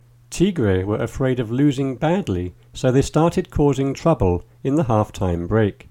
DICTATION 7